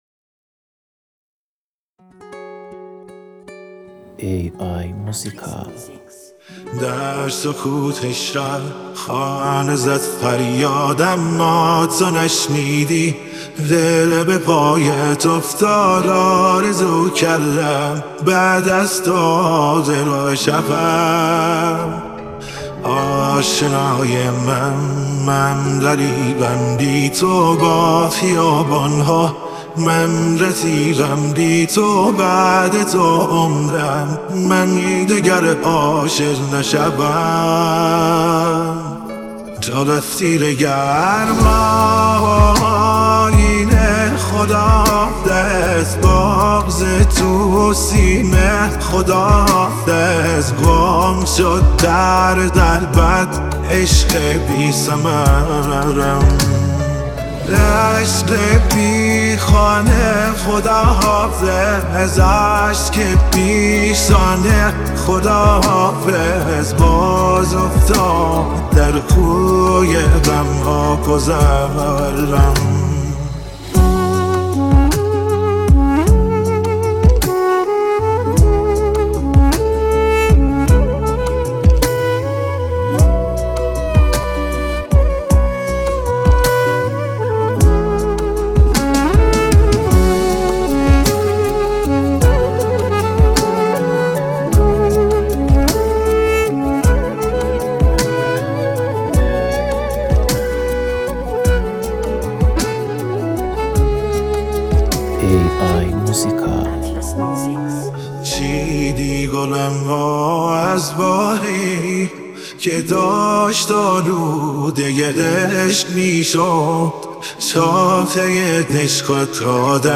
این آهنگ با هوش مصنوعی ساخته شده است